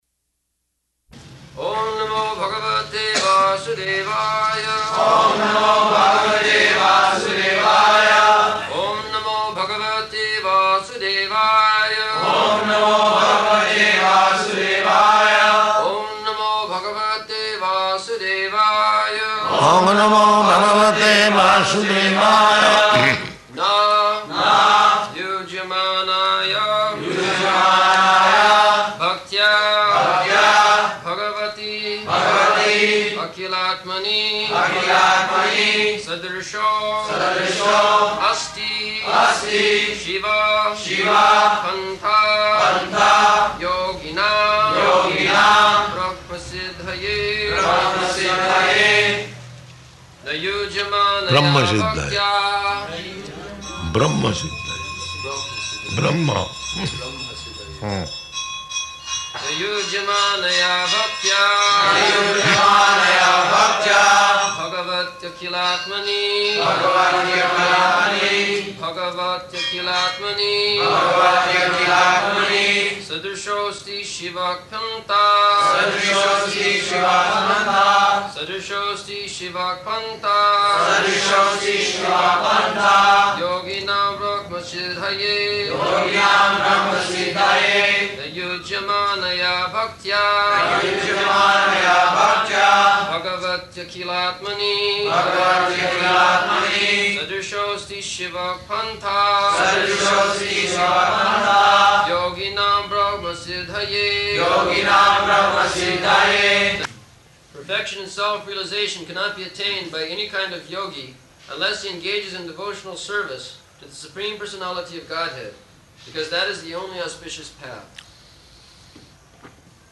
-- Type: Srimad-Bhagavatam Dated: November 19th 1974 Location: Bombay Audio file